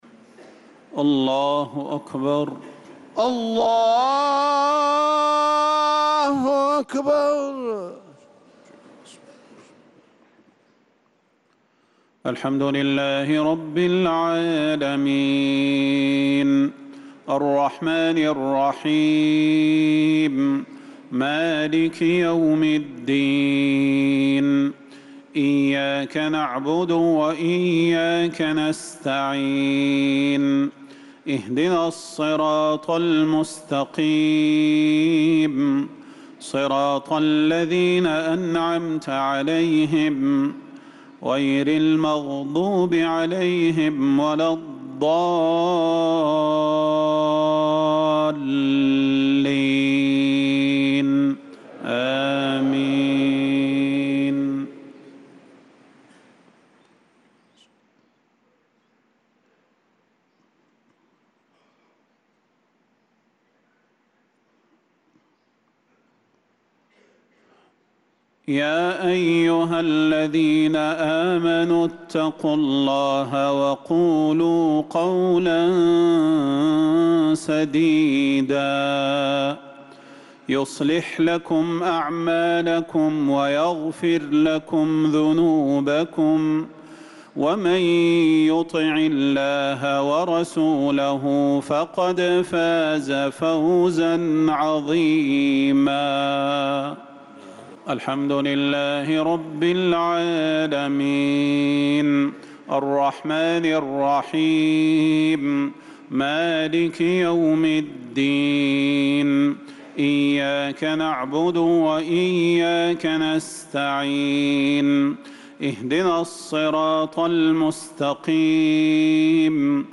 صلاة المغرب للقارئ صلاح البدير 6 ربيع الأول 1446 هـ